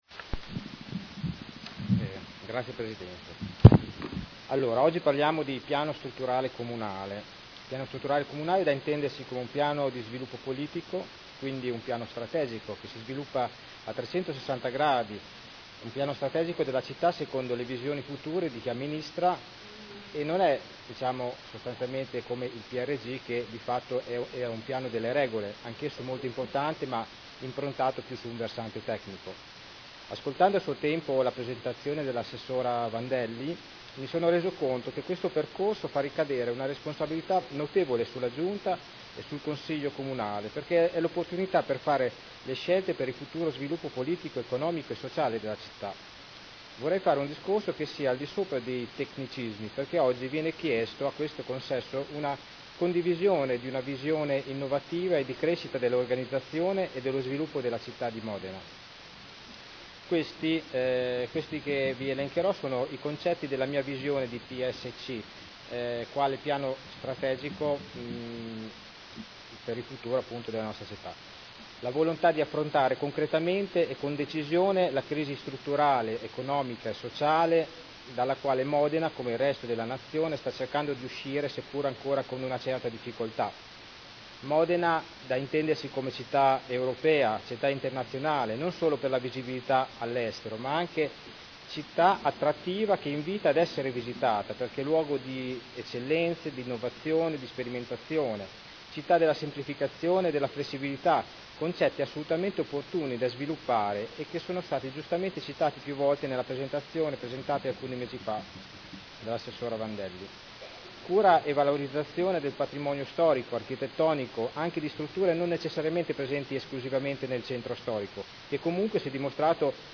Vincenzo Stella — Sito Audio Consiglio Comunale